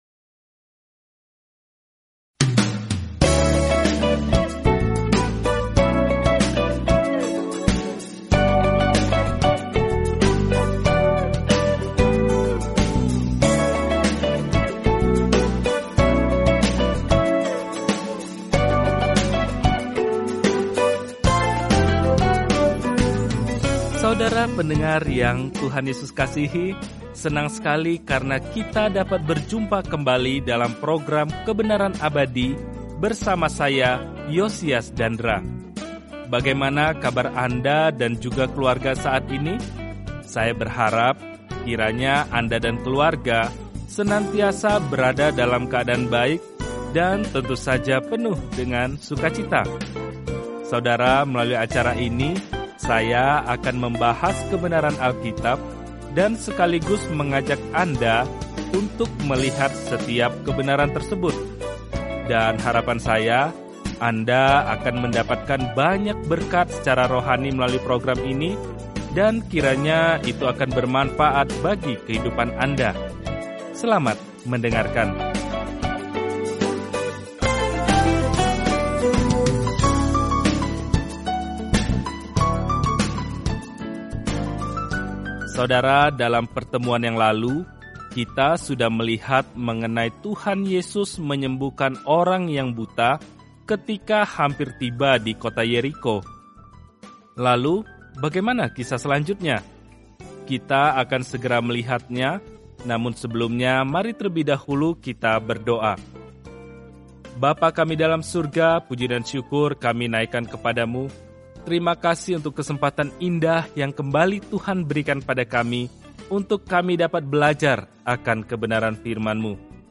Firman Tuhan, Alkitab Lukas 19 Hari 21 Mulai Rencana ini Hari 23 Tentang Rencana ini Para saksi mata menginformasikan kabar baik yang diceritakan Lukas tentang kisah Yesus sejak lahir, mati, hingga kebangkitan; Lukas juga menceritakan kembali ajaran-Nya yang mengubah dunia. Telusuri Lukas setiap hari sambil mendengarkan pelajaran audio dan membaca ayat-ayat tertentu dari firman Tuhan.